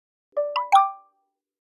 Уведомления, оповещения, сообщения гугл почты Gmail в mp3